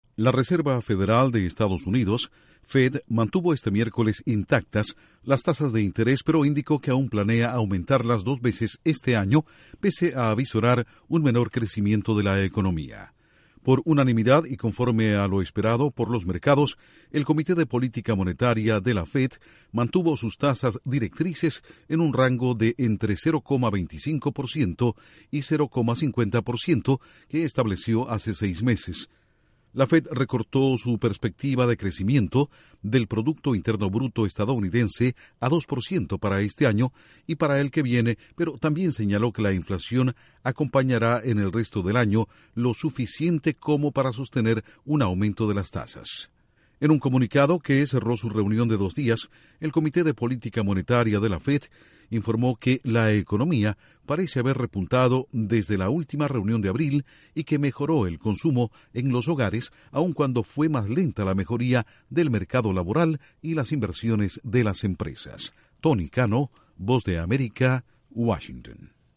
La Reserva Federal de Estados Unidos mantiene intactas las tasas de interés. Informa desde la Voz de América en Washington